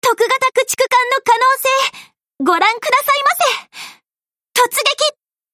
Ship_Voice_Shirayuki_Kai_Ni_Night_Battle.mp3